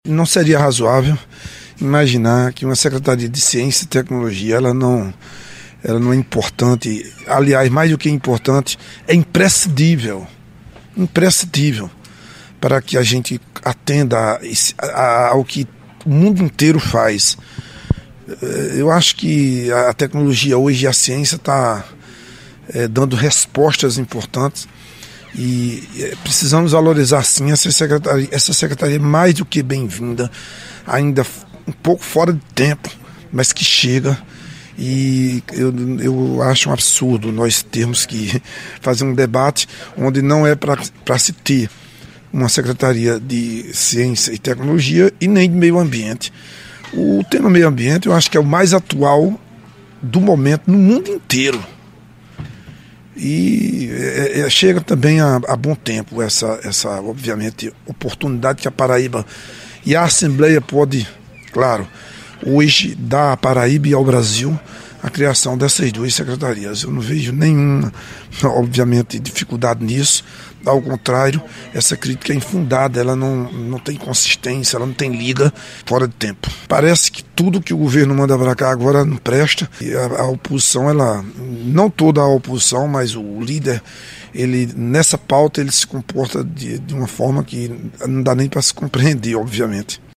Essa crítica é infundada”, disse o parlamentar em entrevista ao Correio Debate.